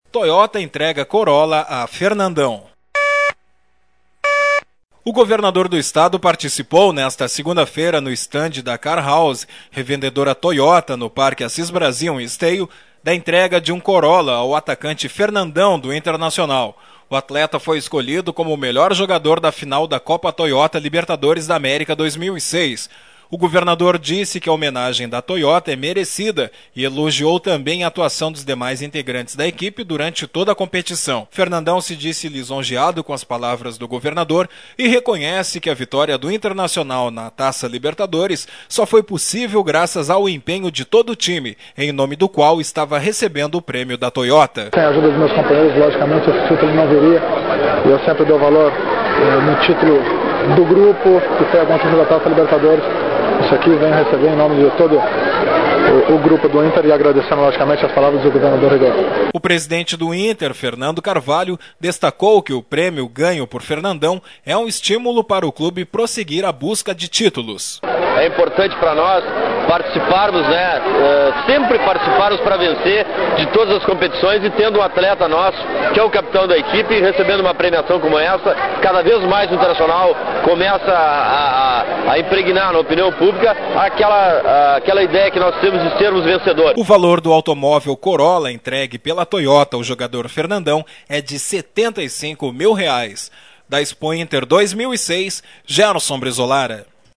O governador do Estado participou, nesta 2ª feira, no estande da Car House, revendedora Toyota, no Parque Assis Brasil, em Esteio, da entrega de um Corola ao atacante Fernandão, do Internacional. Sonora: Fernandão, jogador do S.C. Internacional e Ferna